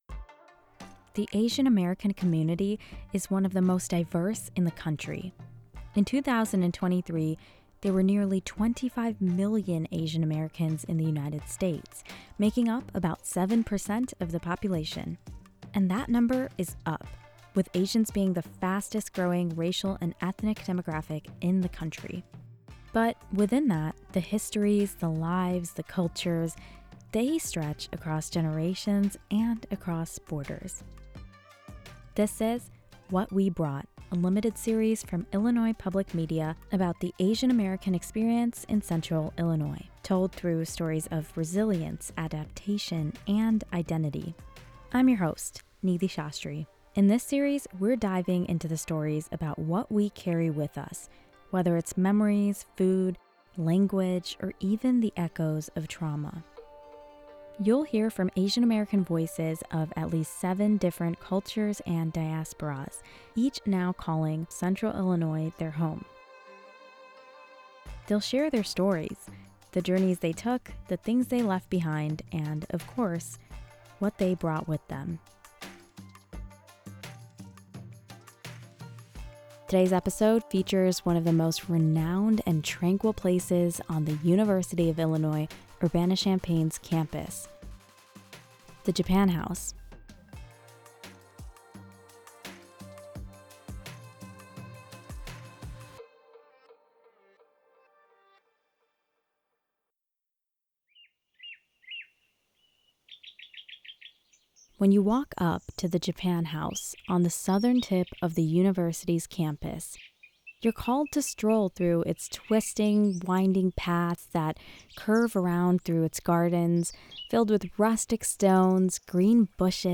The Asian American voices featured come from at least seven different cultures, each now calling Central Illinois their home.